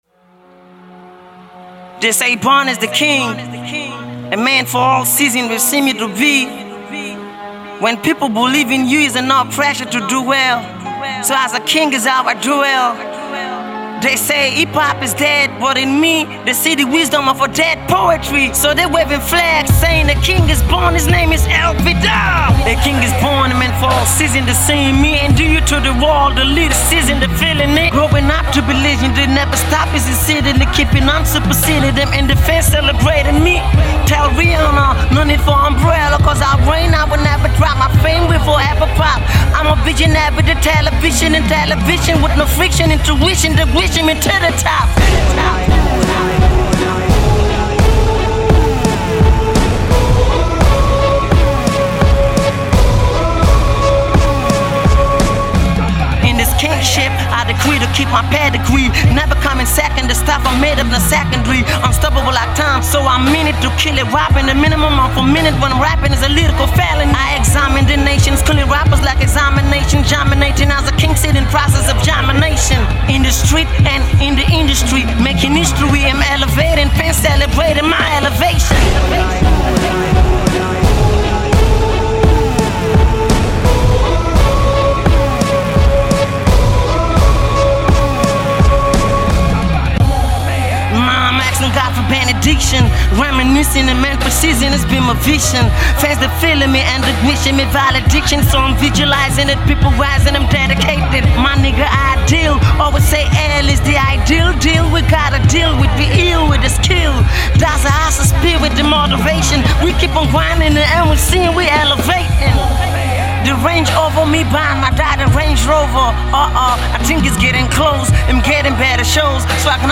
THIS IS THE REBIRTH OF HIP HOP